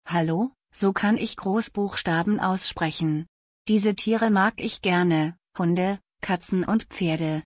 WOW! Ich muss sagen, das klingt schon verdammt gut :-)